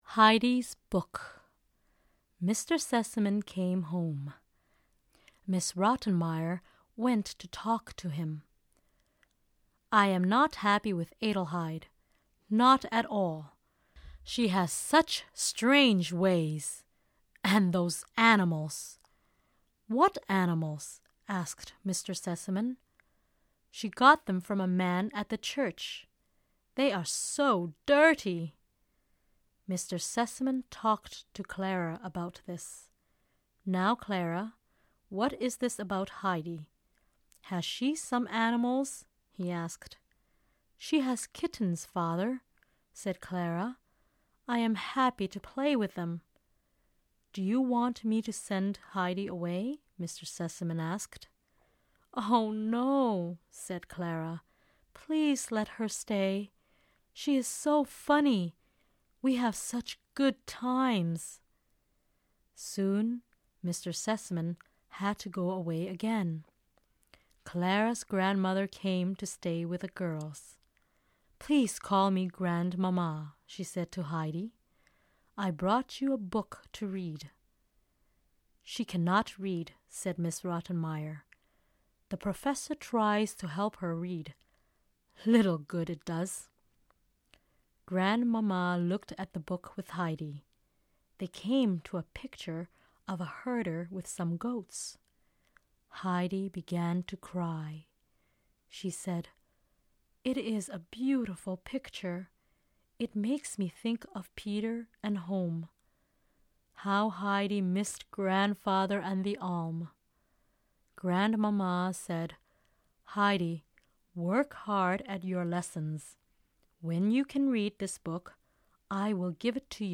This 10 chapter classic introduces great literature while improving listening skills. Expertly paced narration and exciting sound effects keep interest high. Each audio CD: Includes a word-for-word reading directly from the chapter pages in EDCON's Classic workbook series.